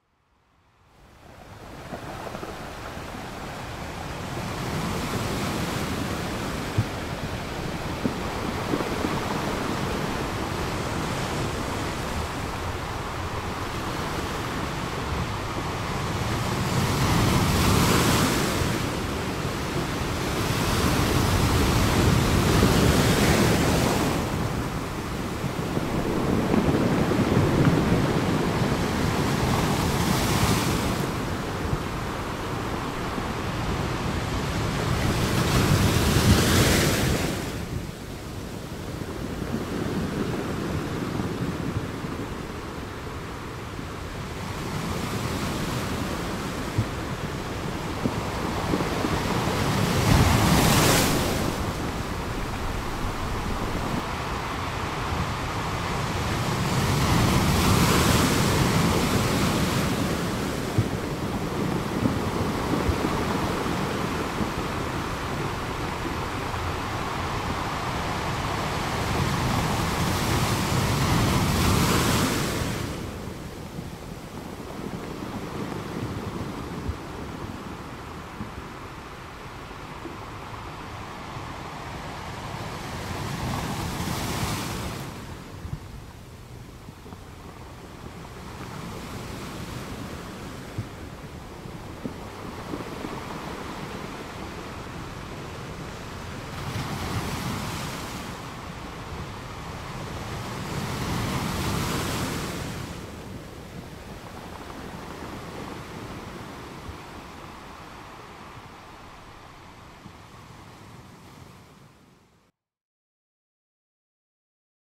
electronic sounds